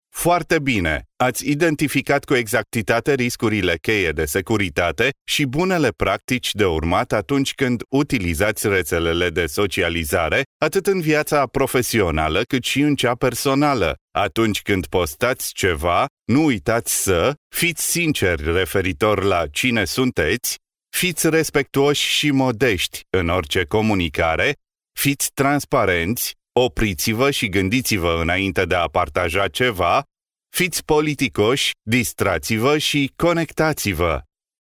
Experienced Romanian male, native, voice talent
Romanian male - elearning